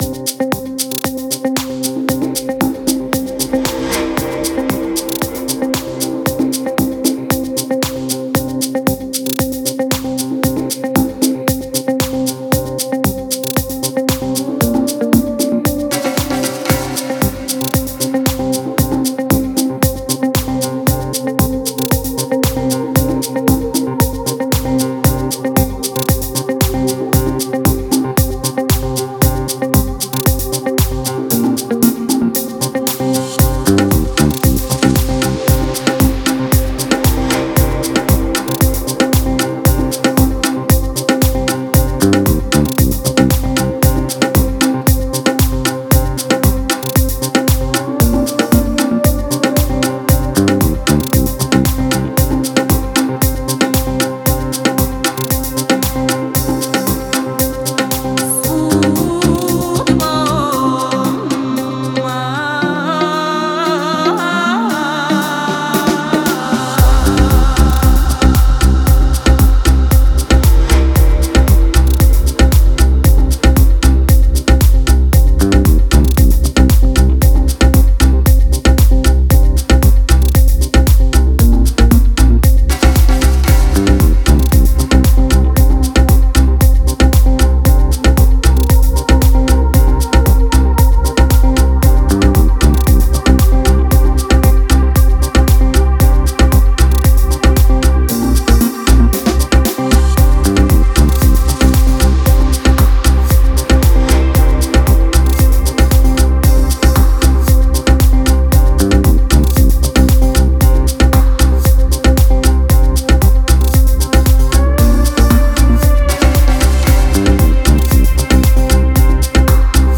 это атмосферная трек в жанре прогрессив-хаус